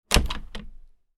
Closing Small Wooden Door Sound Effect
A realistic small wooden door or window closing sound effect adds authentic ambiance to any scene.
Genres: Sound Effects
Closing-small-wooden-door-sound-effect.mp3